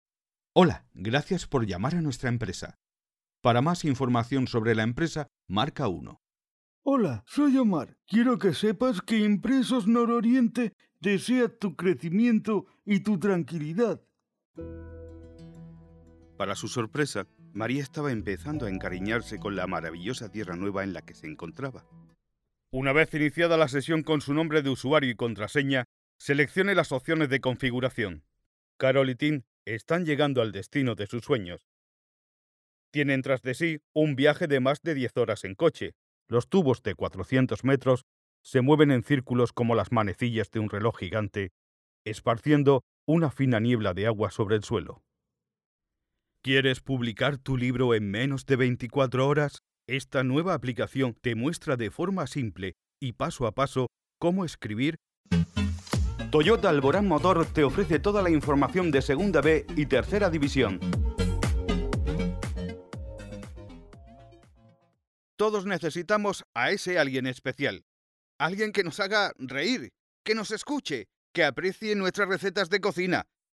español neutro masculino hombre personajes narrador documental comercial
Sprechprobe: Sonstiges (Muttersprache):